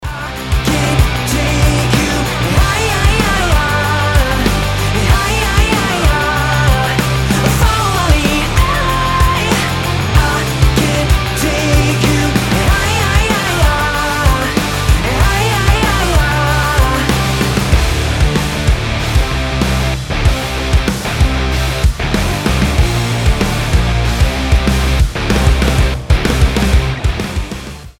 • Качество: 320, Stereo
мужской вокал
громкие
Alternative Rock